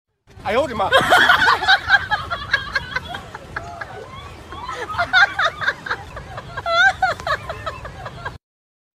Ayonima (comedy sound 🤣)
Ayonima-comedy-sound-effect.mp3